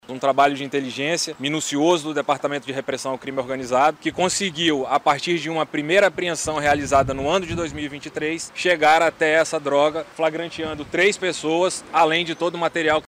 O balanço da operação foi apresentado nessa segunda-feira, em coletiva de imprensa na sede do Departamento de Repressão ao Crime Organizado (DRCO).
O delegado-geral Bruno Fraga disse que a Operação Jomini surgiu de uma apreensão realizada no ano passado: (Ouça)